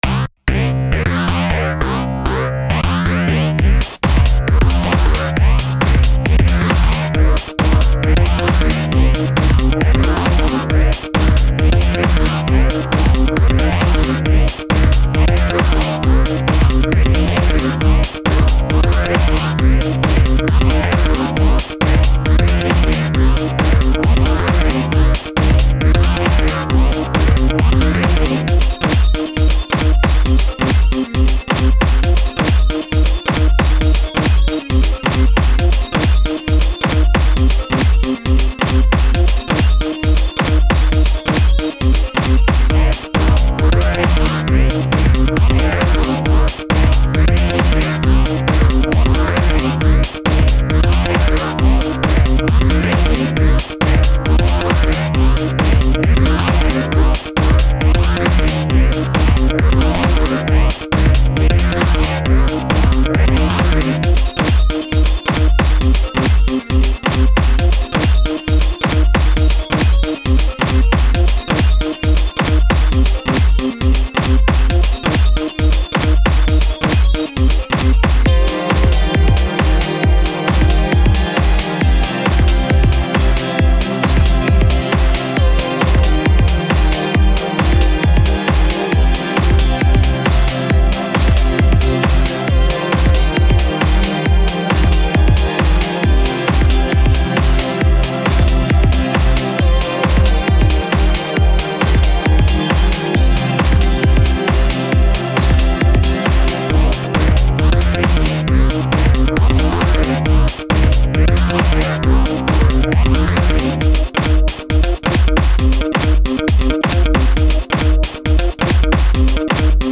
• Music is loop-able, but also has an ending